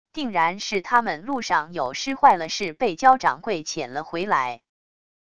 定然是他们路上有失坏了事被焦掌柜遣了回来wav音频生成系统WAV Audio Player